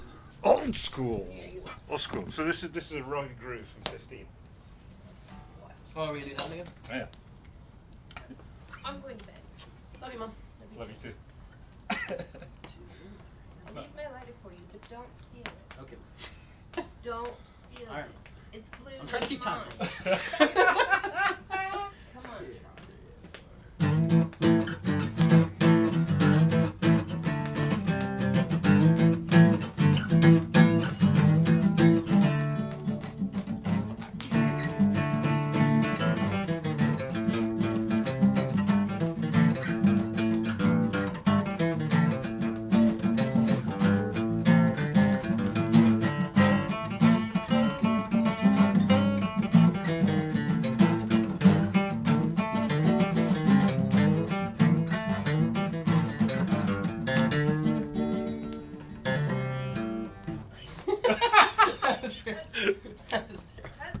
Blues Riff